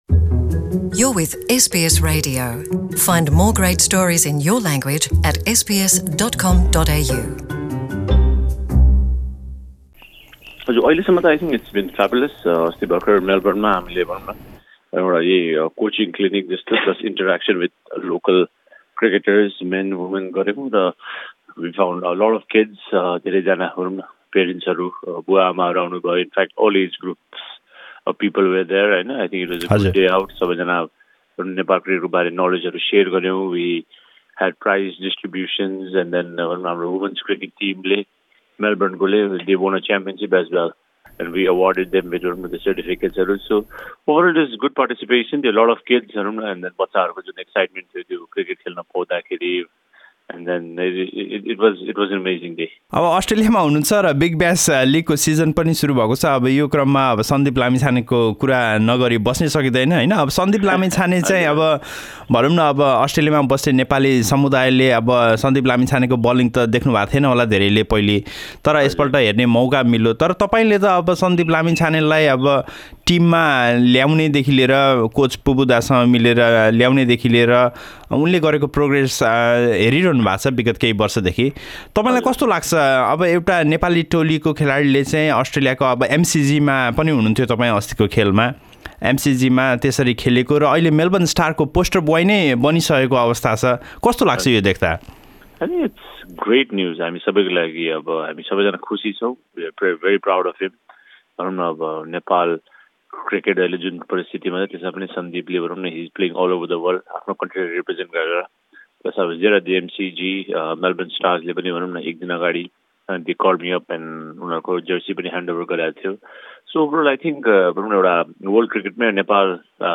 उहाँसँग गरिएको हाम्रो कुराकानी सुन्न माथि रहेको मिडिया प्लेयरमा प्ले बटन थिच्नुहोस् नेपाली क्रिकेट टोलीका क्याप्टेन पारस खड्काको अस्ट्रेलियासँगको सम्बन्ध अनौठो रहेको छ।